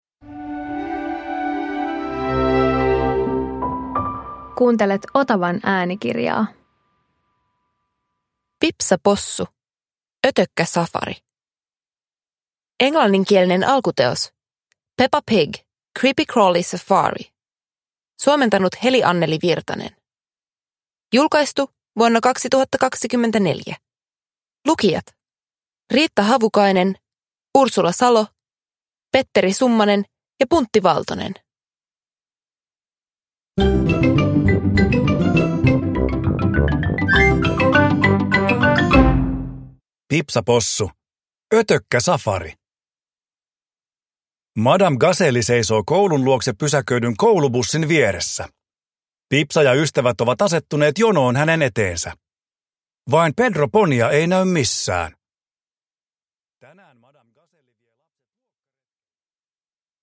Pipsa Possu - Ötökkäsafari – Ljudbok